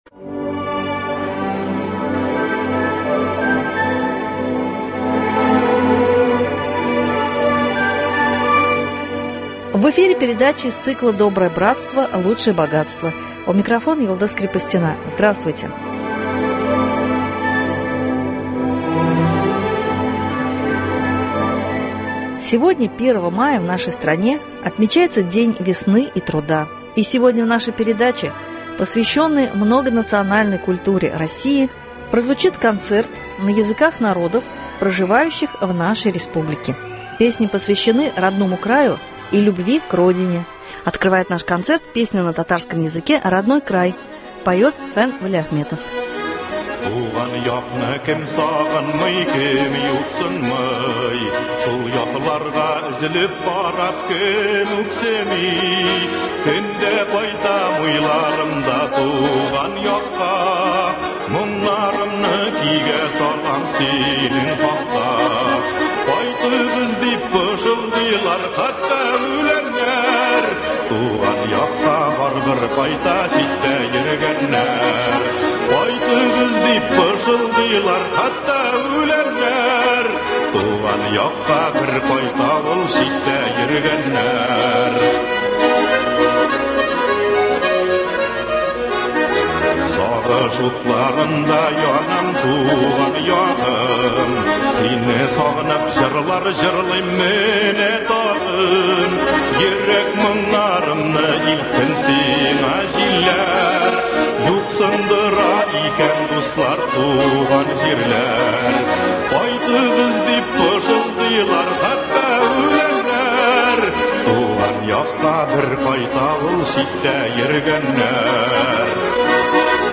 Концерт.